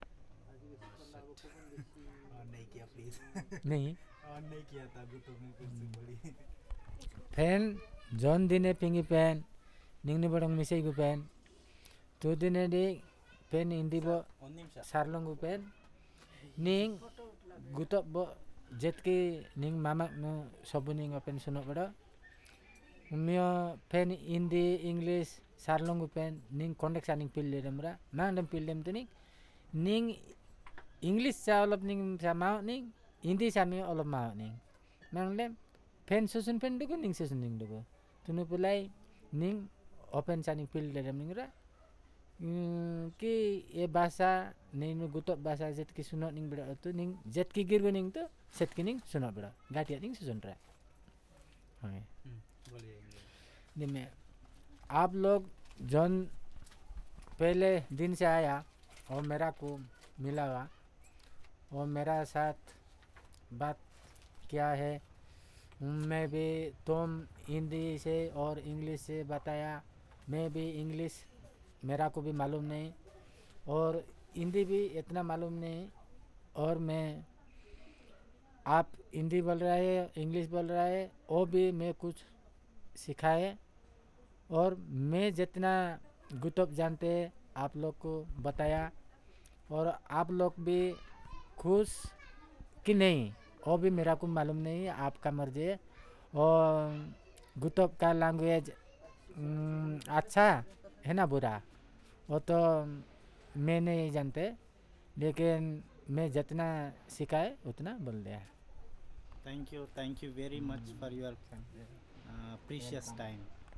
Personal narration on the experiences with the fieldworkers